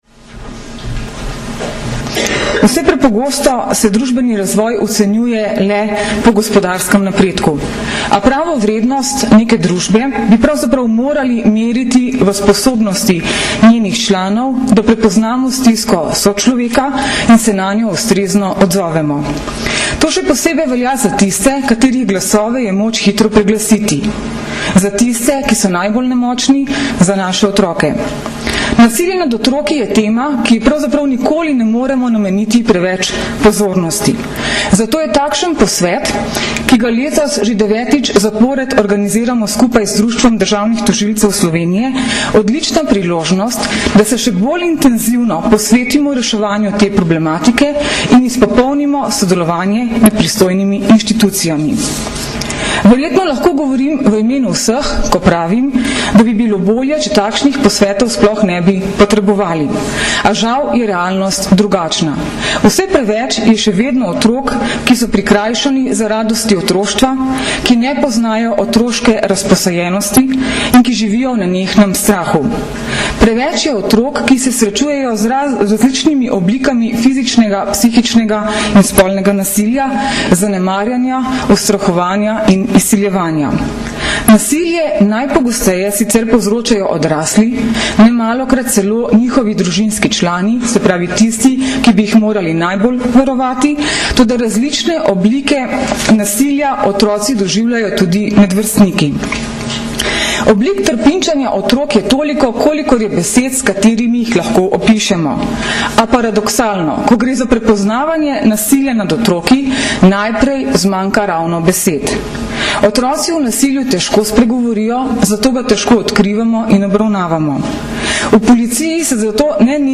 Danes, 9. aprila 2010, se je zaključil dvodnevni posvet z naslovom Nasilje nad otroki - razumeti in prepoznati, ki sta ga na Brdu pri Kranju organizirala Generalna policijska uprava in Društvo državnih tožilcev Slovenije.
Zvočni posnetek govora namestnice generalnega direktorja policije mag. Tatjane Bobnar (mp3)